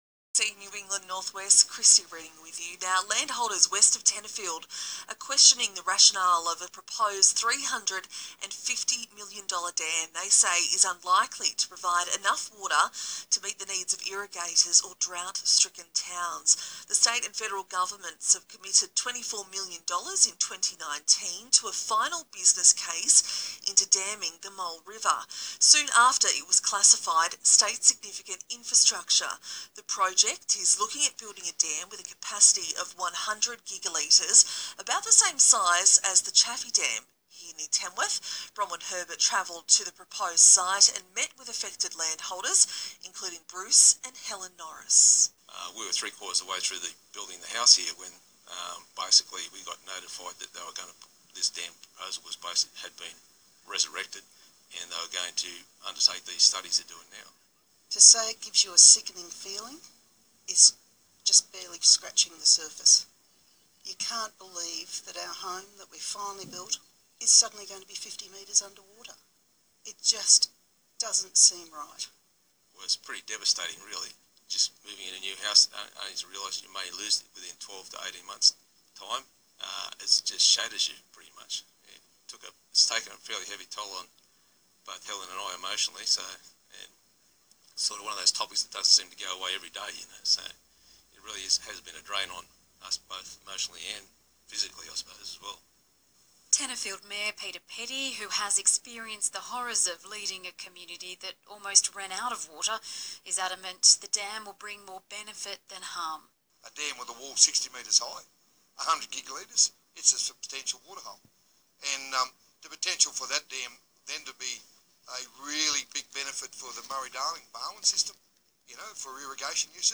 ABC Radio interview – Mole River Dam 19/2/21